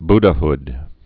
(bdə-hd, bdə-)